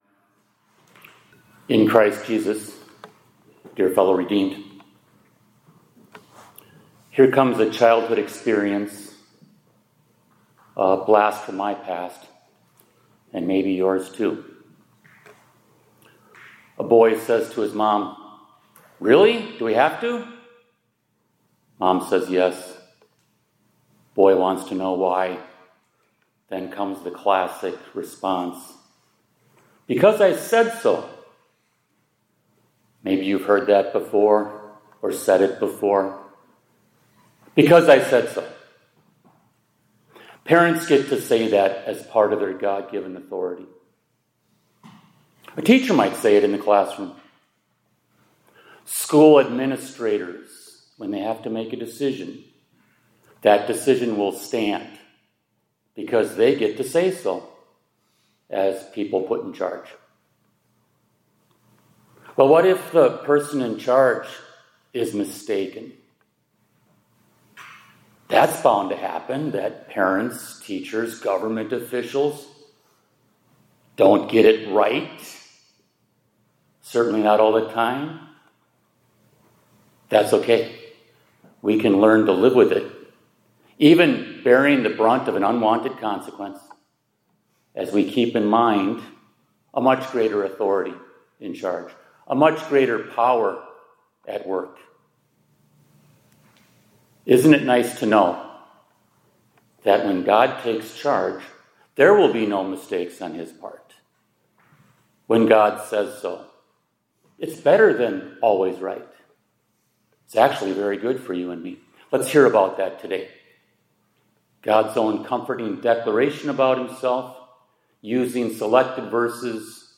2025-10-16 ILC Chapel — God Gets the Say-So